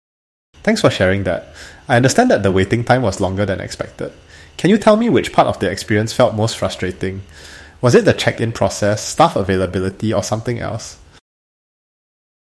These are sample AI-driven voice interactions, adapted dynamically based on how customers respond
Singapore | English | AI intelligently acknowledges feedback and asks a contextual follow-up question